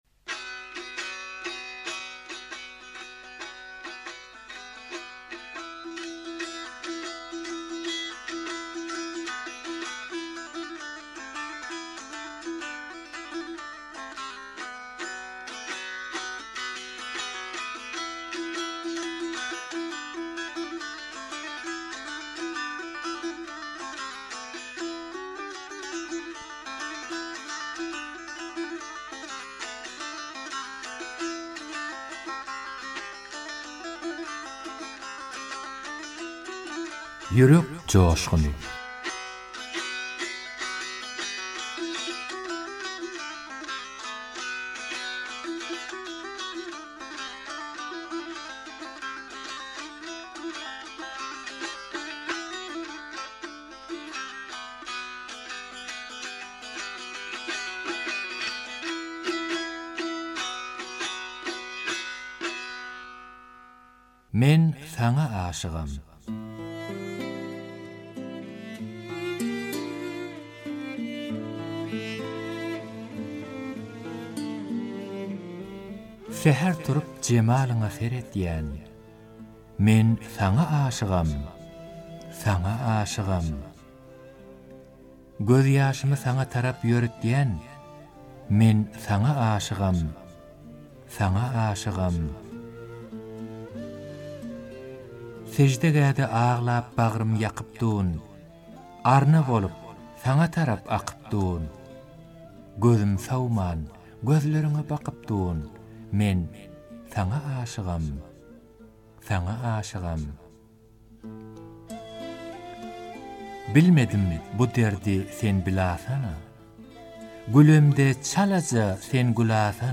goşgy owaz aýdym